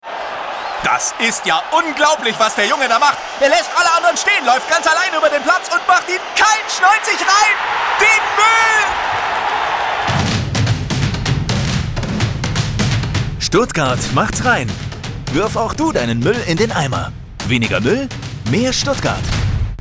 Radio-Spot